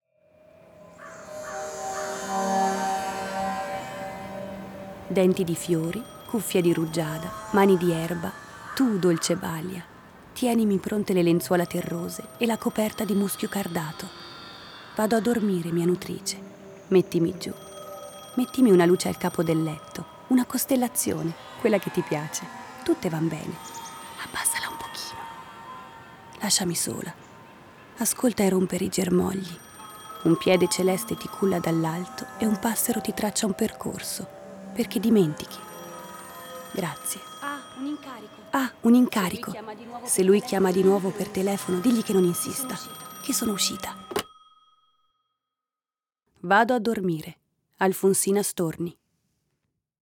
Abbiamo immaginato un contenitore dove si possano ascoltare delle prime letture poetiche.